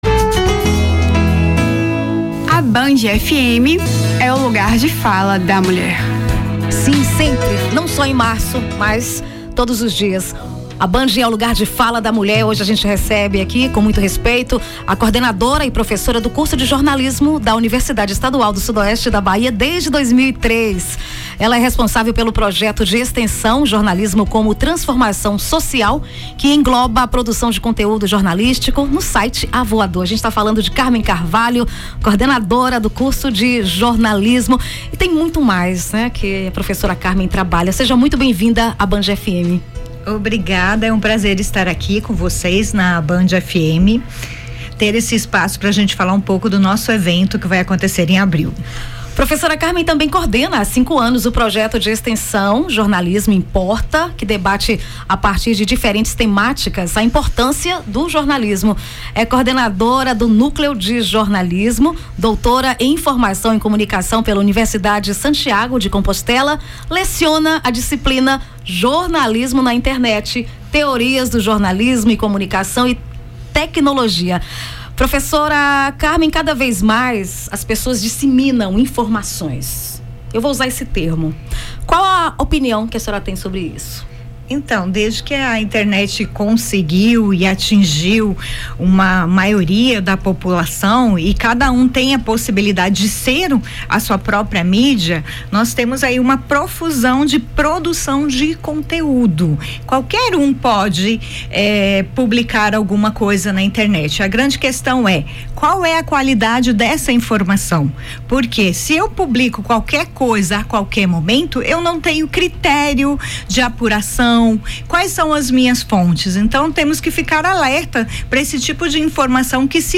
Mês da Mulher na Band FM: Entrevista